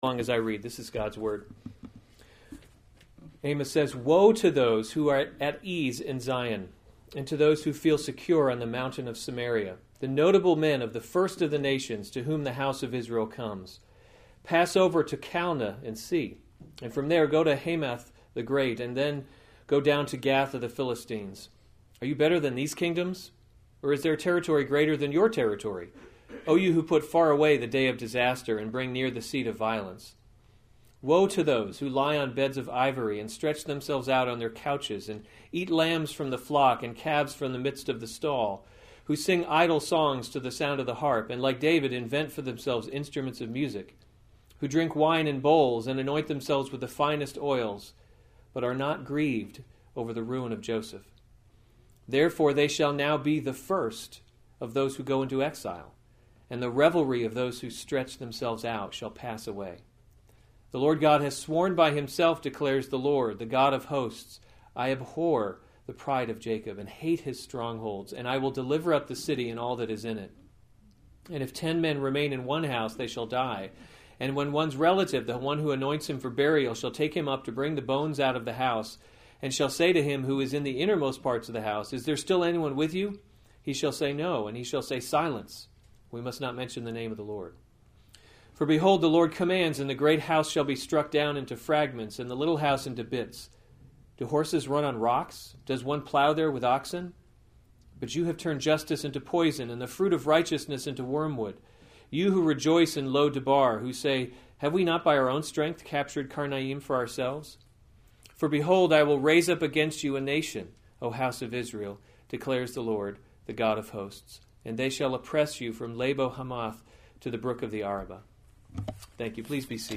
October 31, 2015 Amos: He’s Not a Tame Lion series Weekly Sunday Service Save/Download this sermon Amos 6:1-14 Other sermons from Amos Woe to Those at Ease in Zion 6:1 “Woe […]